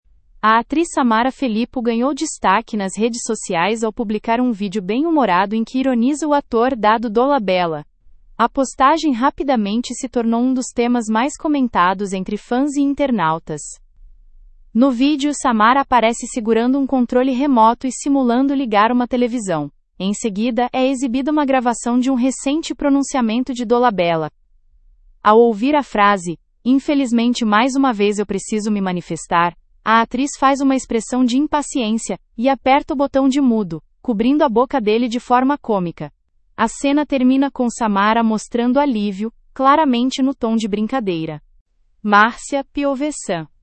Em seguida, é exibida uma gravação de um recente pronunciamento de Dolabella. Ao ouvir a frase “infelizmente mais uma vez eu preciso me manifestar”, a atriz faz uma expressão de impaciência e aperta o botão de mudo, cobrindo a boca dele de forma cômica.